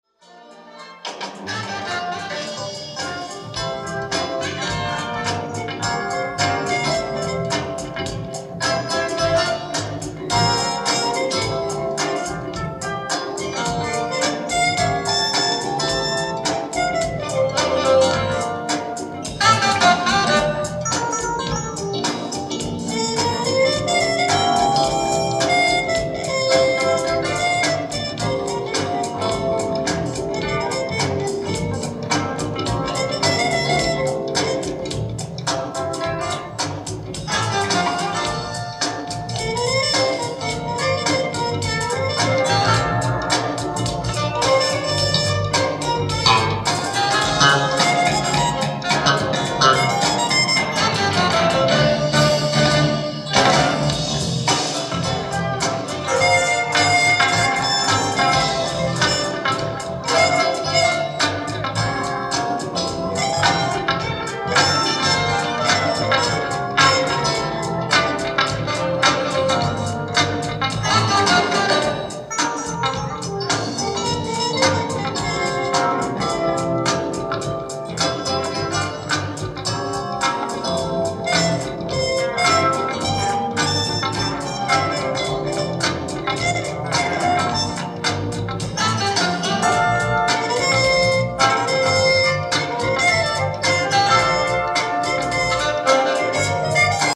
※試聴用に実際より音質を落としています。